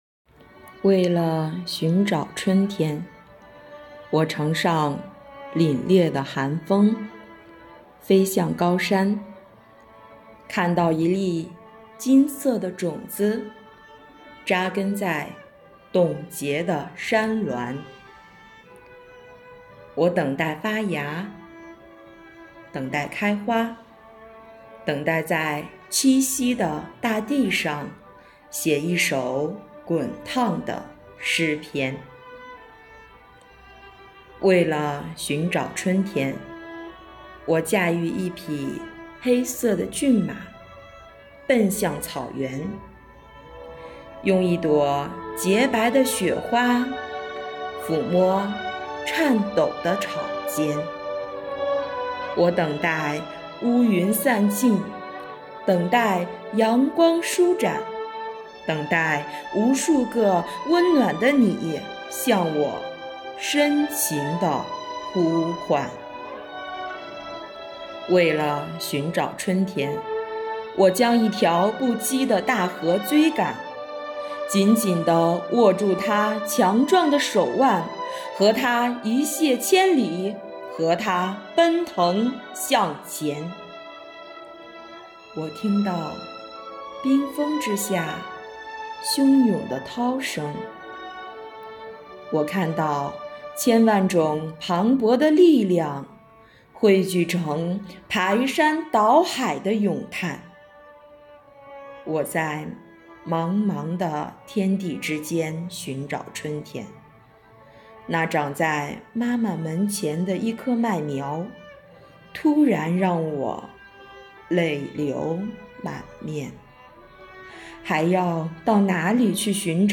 以朗诵为载体，用诗篇来明志
朗诵